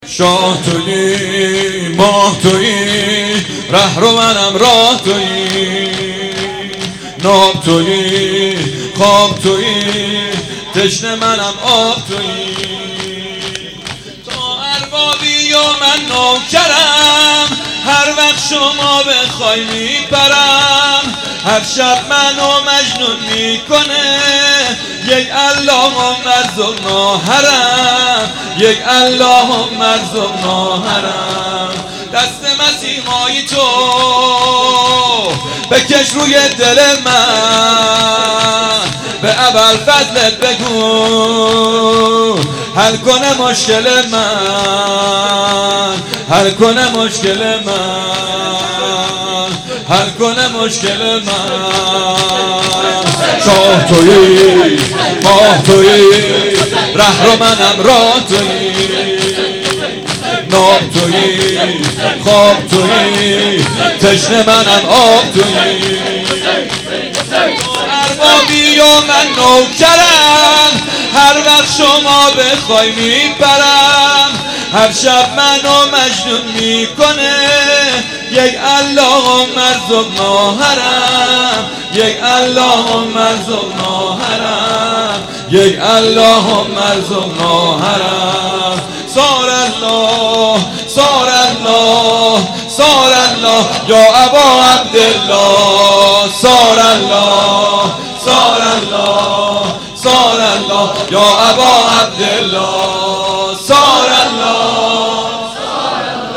شور 2 شب هفتم محرم 94